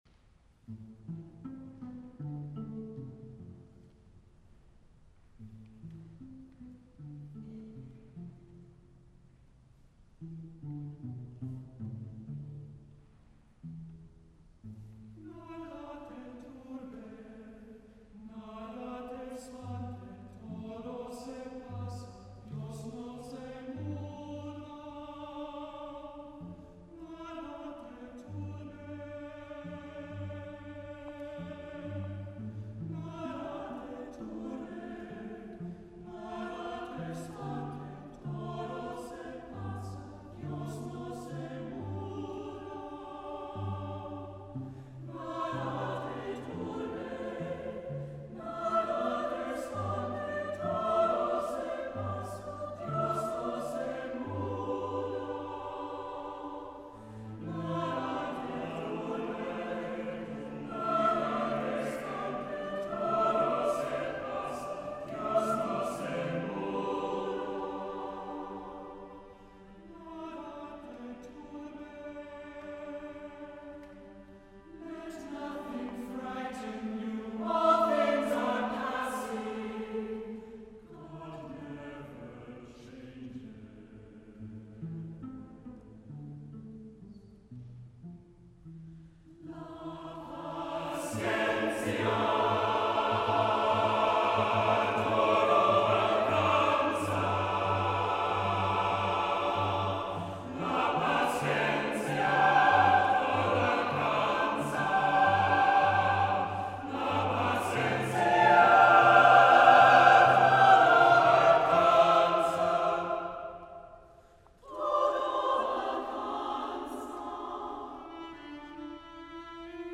women's choir
SSAA, cello
SATB, cello 4:15 $2.60